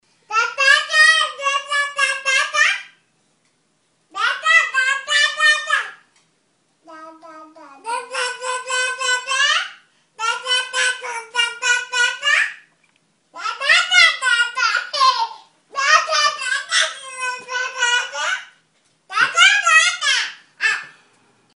Звуки младенцев
Малыши забавно болтают